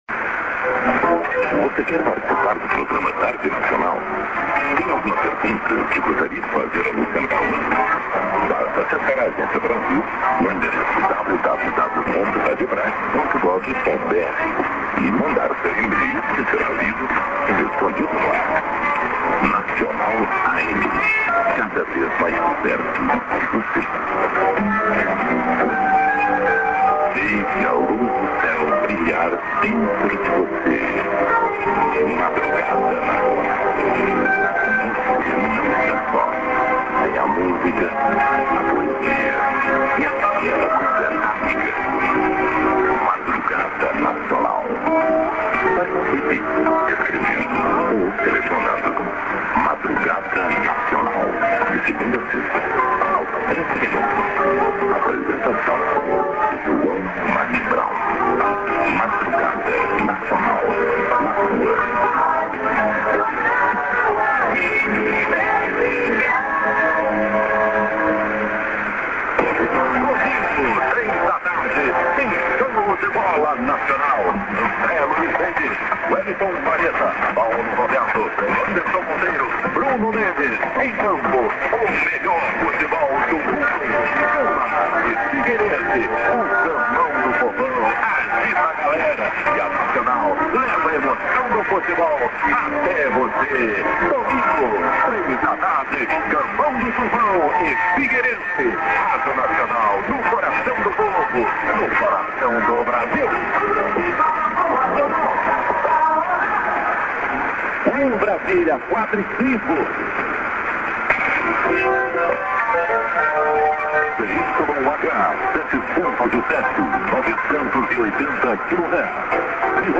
ID+ADDR(man)->ID:radio National(man)->SKJ+ID:Radio Nac.da Amazonia(man)->